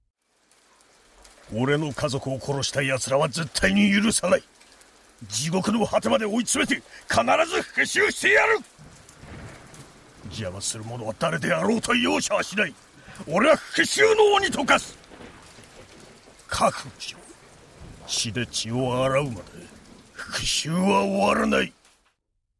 Voice Samples
５０代～
マフィアのボス
マフィア.mp3